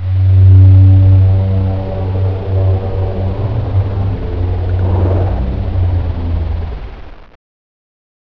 RobotWhaleScream-002.wav